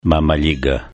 VEĆ I PO TOME DA SE SVAKA OBRAĐENA REČ MOŽE ČUTI KAKO ZVUČI!